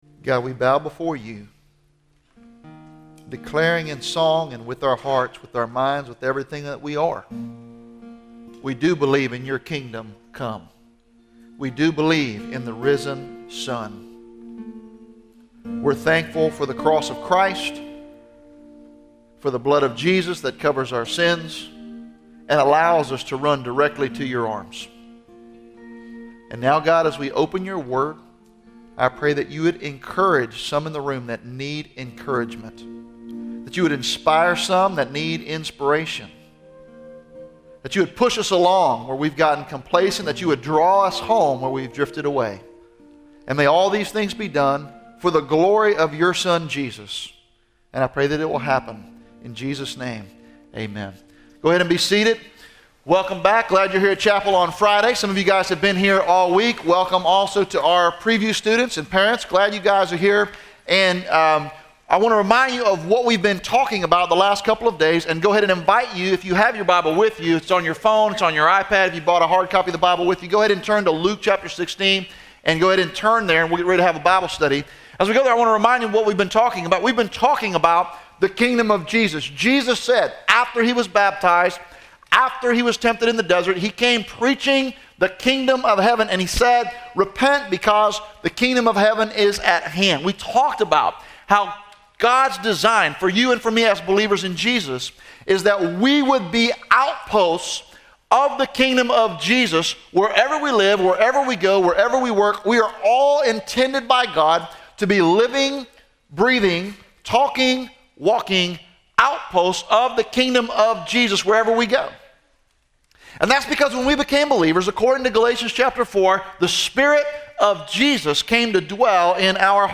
Faith in Practice Chapel
Address: "The Stakes are High" from Luke 16